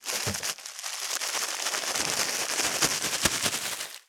662コンビニ袋,ゴミ袋,スーパーの袋,袋,買い出しの音,ゴミ出しの音,袋を運ぶ音,
効果音